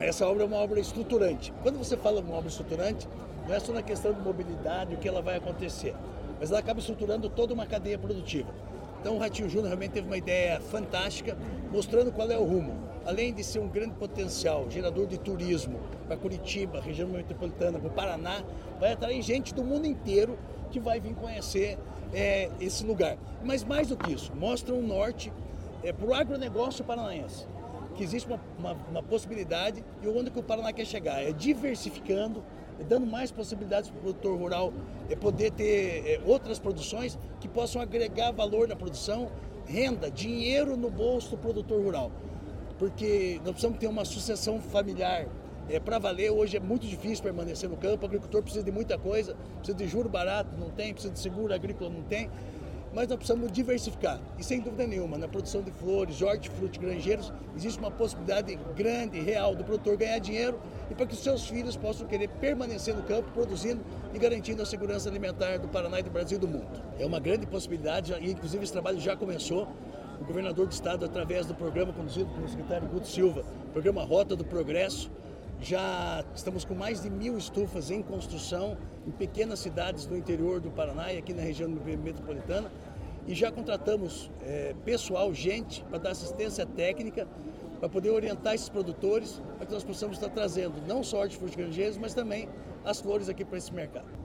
Sonora do secretário da Agricultura e do Abastecimento, Marcio Nunes, sobre o anúncio do novo Mercado de Flores da Ceasa